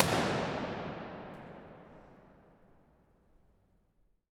Reverbs from around the world…
Lutheran Church of Iceland, In Reykjavik.
hallgrimskirkja_-_Reykjavik.wav